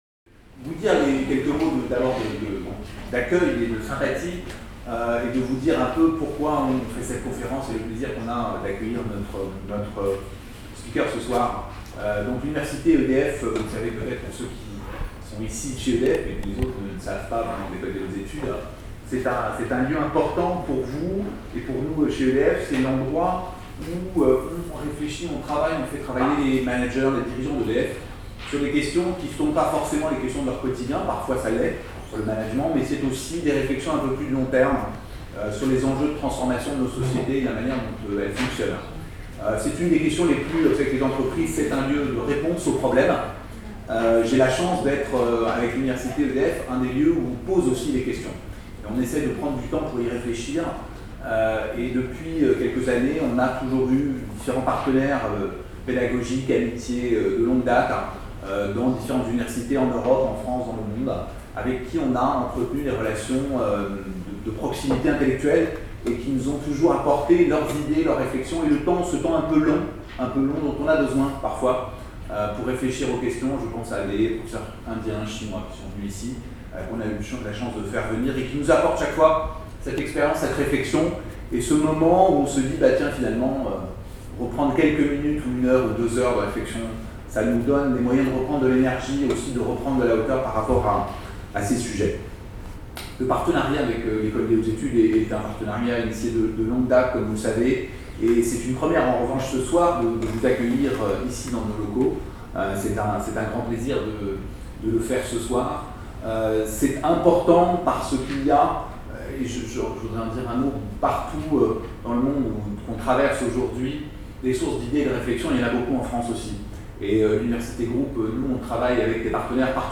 Séminaire